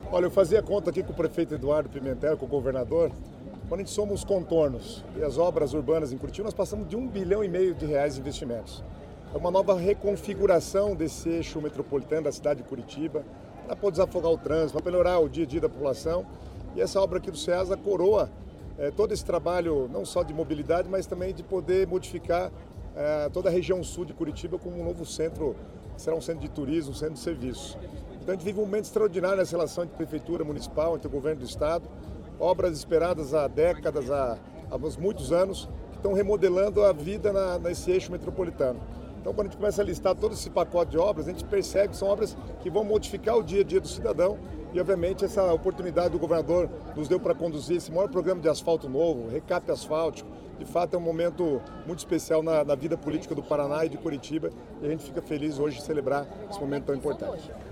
Sonora do secretário das Cidades, Guto Silva, sobre o anúncio do novo Mercado de Flores da Ceasa